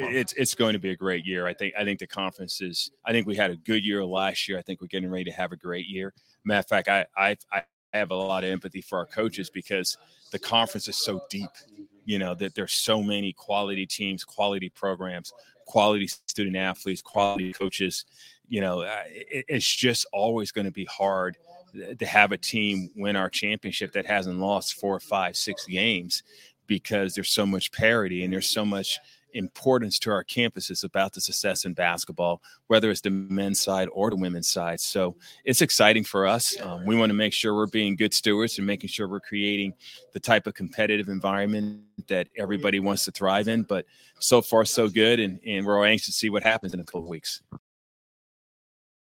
This conversation took place at the Valley men’s basketball media day.